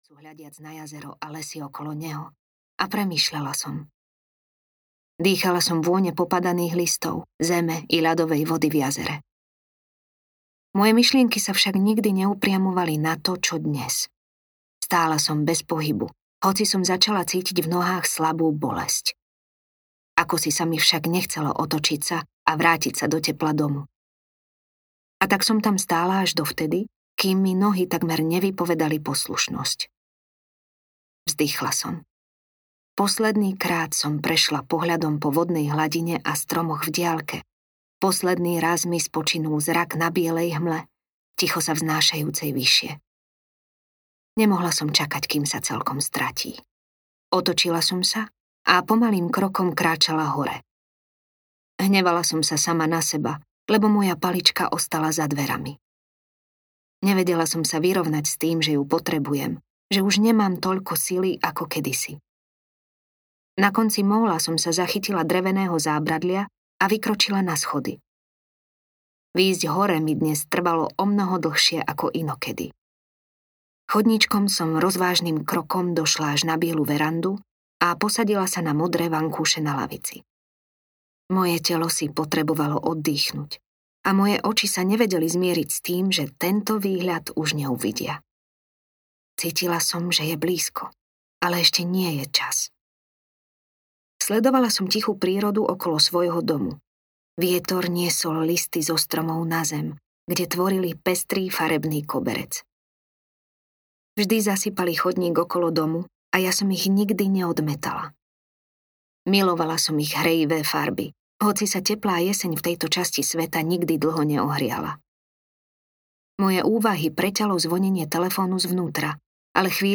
ZlatovláSSka audiokniha
Ukázka z knihy
• InterpretTáňa Pauhofová